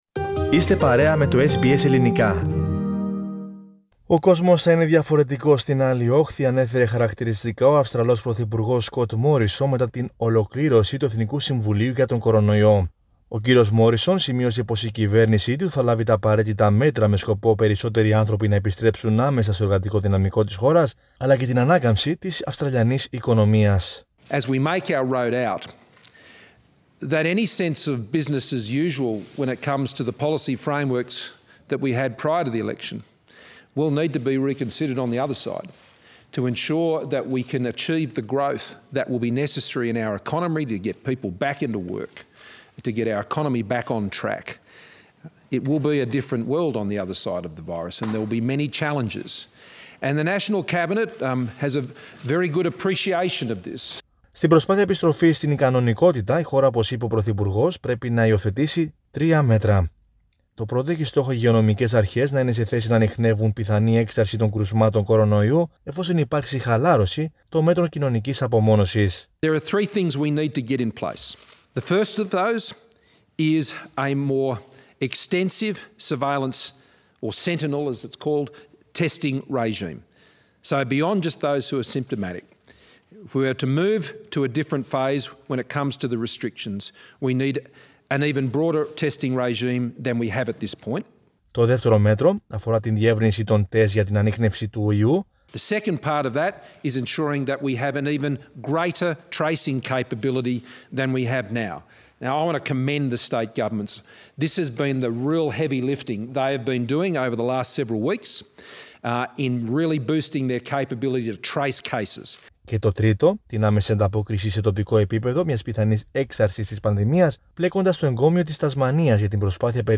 Scott Morrison talks to reporters.